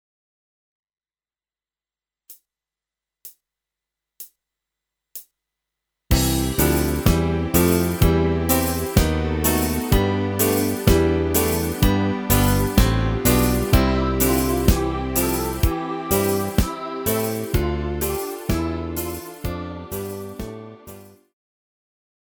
Rubrika: Pop, rock, beat
- Pre deti (Školkárov)